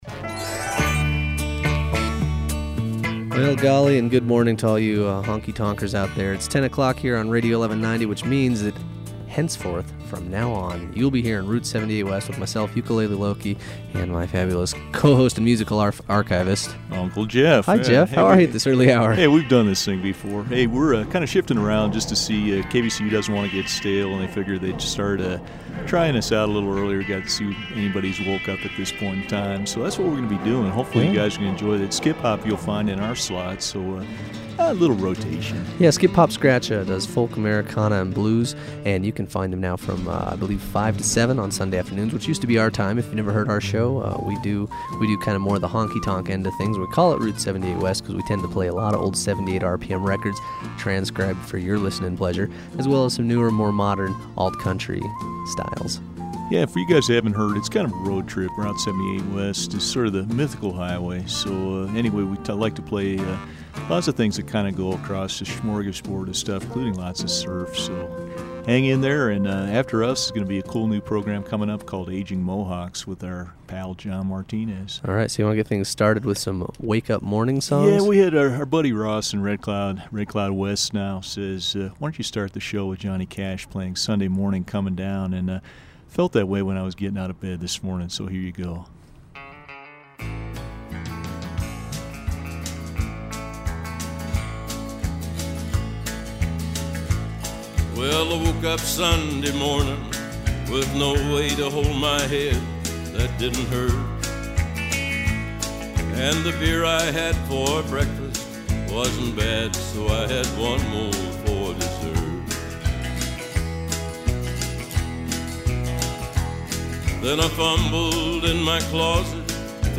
Route 78 West, the best Alt-Country Music Show on the dial.